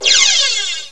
laser01.wav